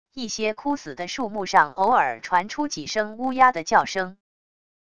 一些枯死的树木上偶尔传出几声乌鸦的叫声wav音频